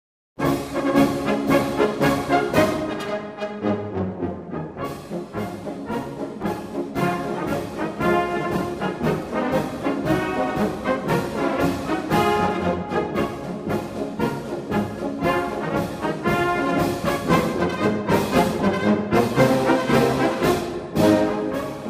Austrian Marches - Spielbeginn.mp3